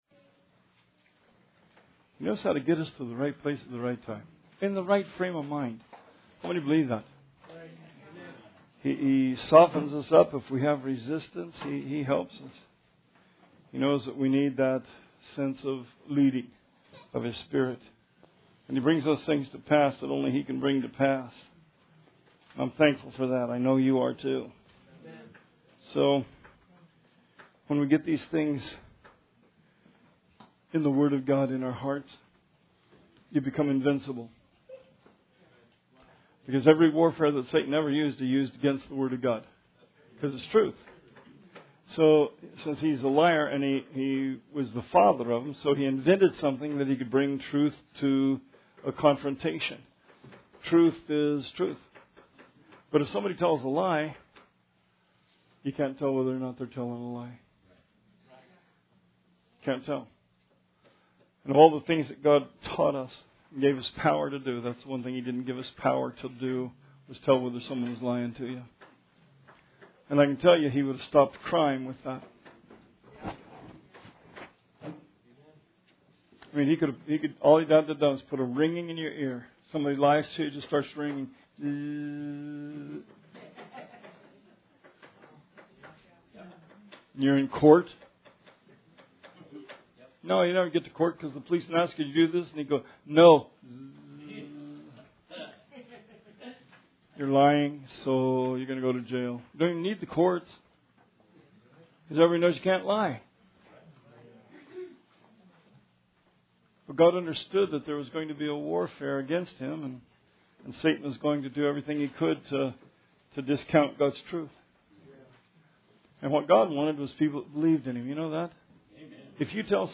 Sermon 4/2/17